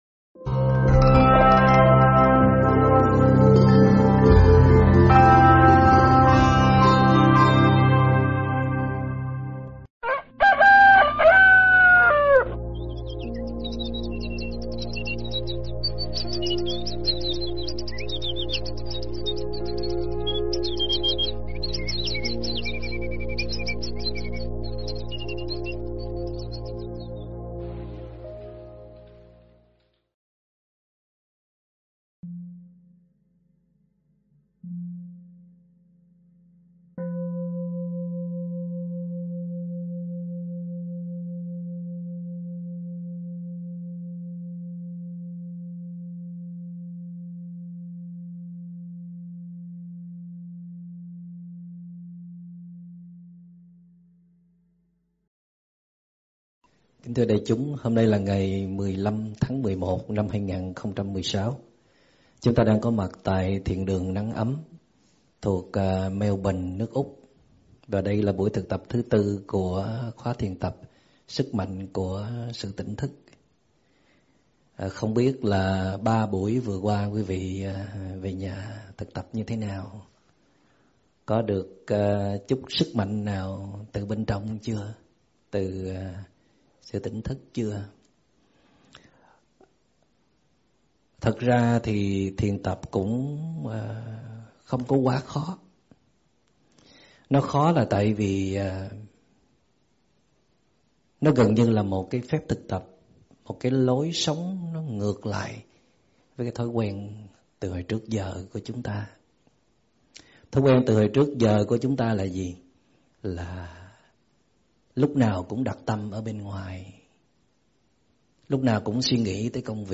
Nghe mp3 thuyết pháp
giảng tại Melbourne